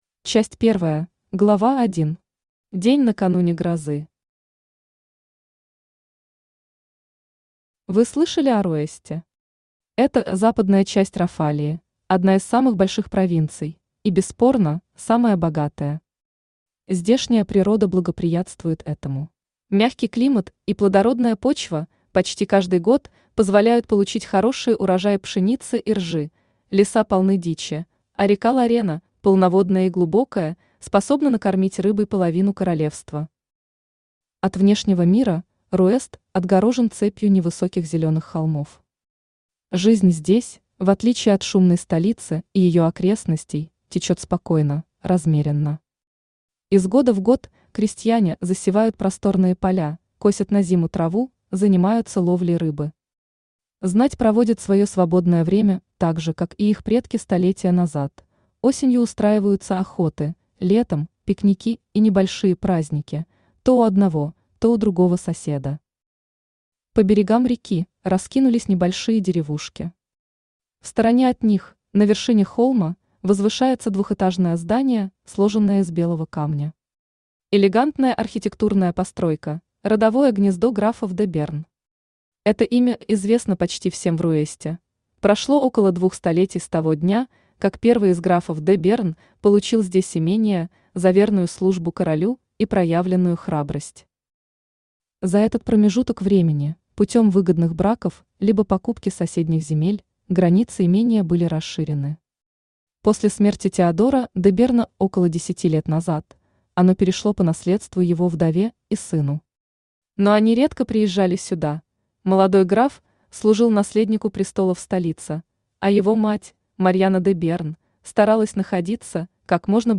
Аудиокнига Азалия, королева сердец. Книга 1 | Библиотека аудиокниг
Книга 1 Автор Татьяна Абиссин Читает аудиокнигу Авточтец ЛитРес.